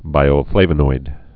(bīō-flāvə-noid)